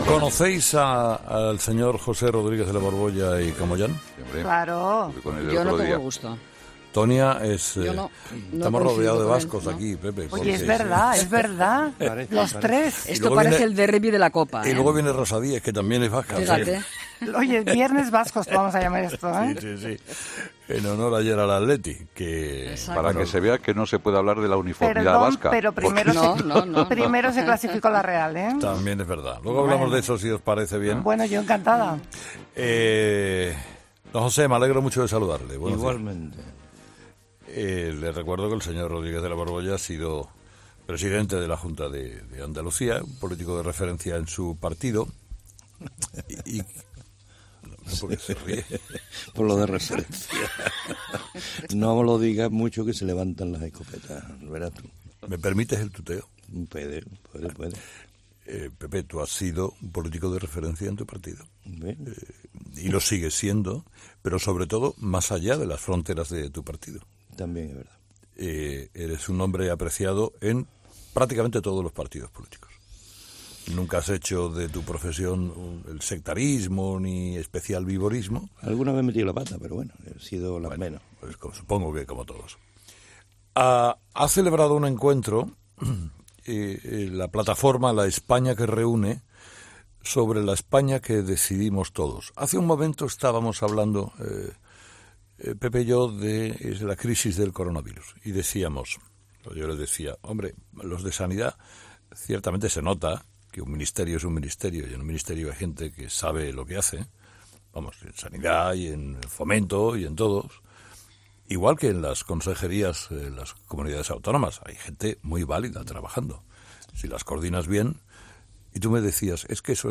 Uno de ellos, José Rodríguez de la Borbolla , ex presidente de la Junta de Andalucía, ha pasado este viernes por los micrófonos de “Herrera en COPE”.